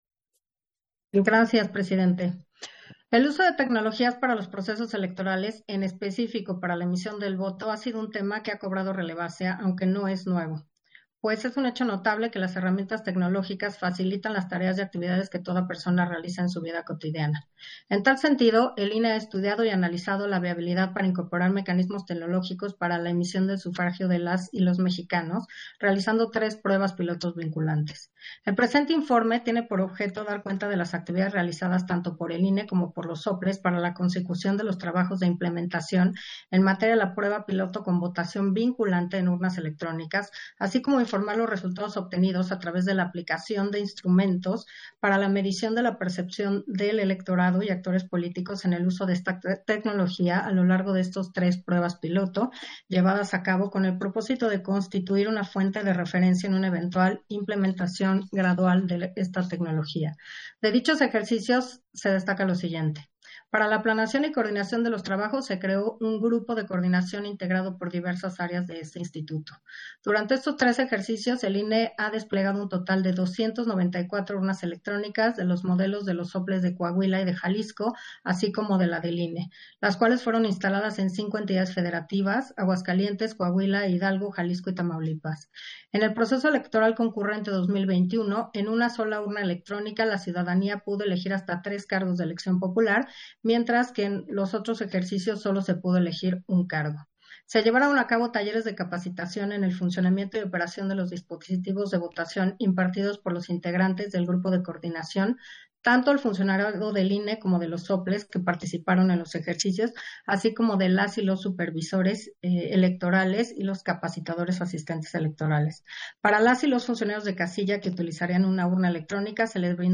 Intervención de Carla Humphrey en el punto 8 de la Sesión Extraordinaria, relativo al informe de evaluación de la implementación del proyecto de voto electrónico